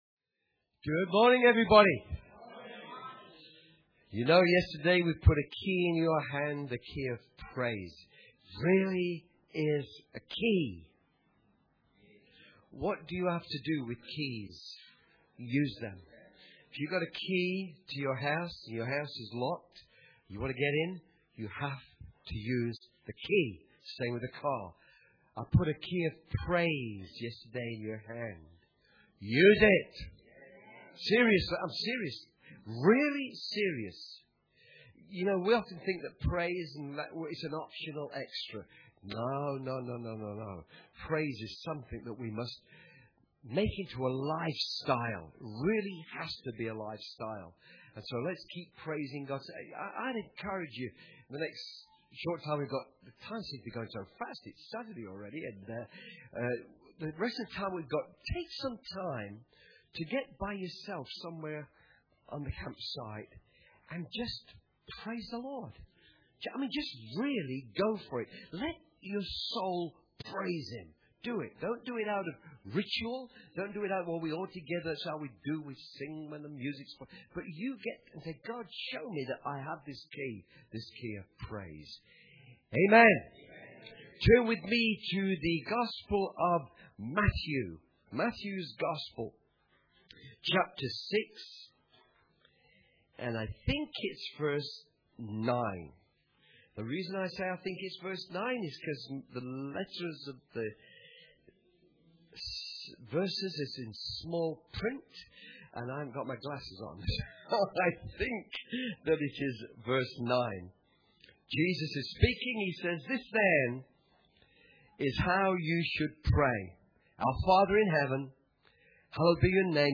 Church Camp 2014 – Saturday_Morn_Session1
Church Camp 2014 - Saturday_Morn_Session1.mp3